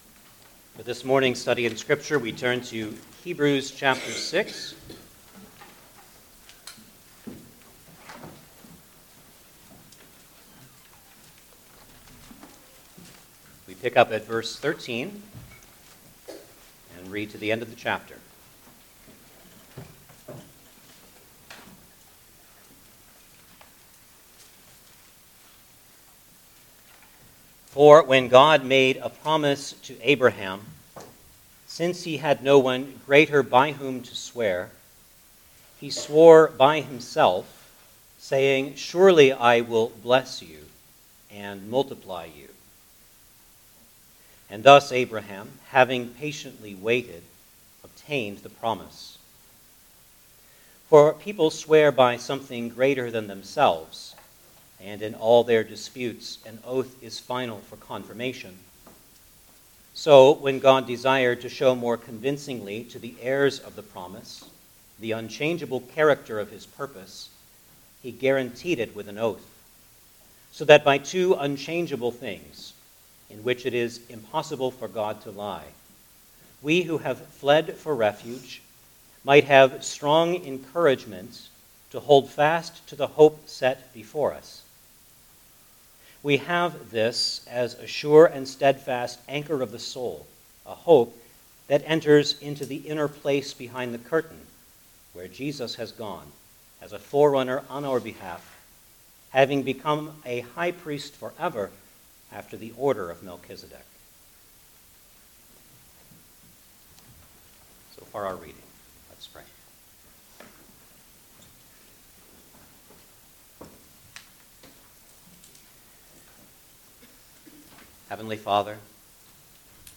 Hebrews Passage: Hebrews 6:13-20 Service Type: Sunday Morning Service Download the order of worship here .